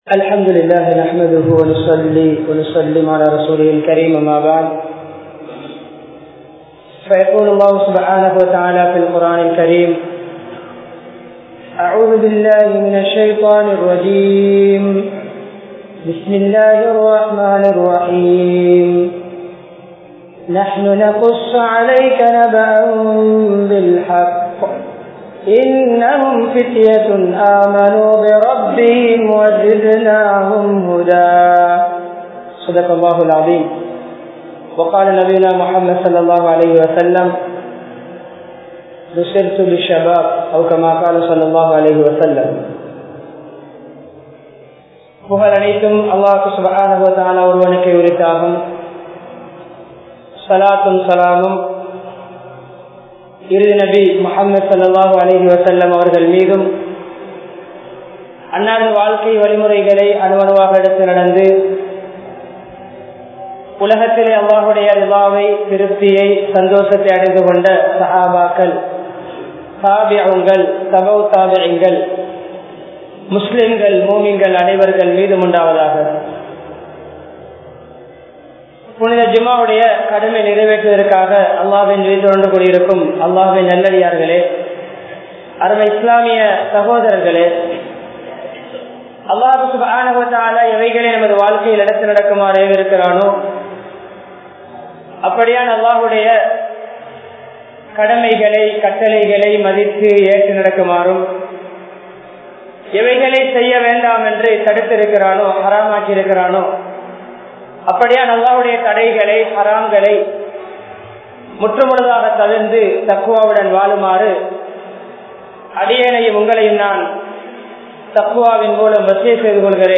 Islaththil Vaalifarhalin Pangalippu (இஸ்லாத்தில் வாலிபர்களின் பங்களிப்பு) | Audio Bayans | All Ceylon Muslim Youth Community | Addalaichenai
Colombo10, Maligawatha, Munawwara Jumua Masjidh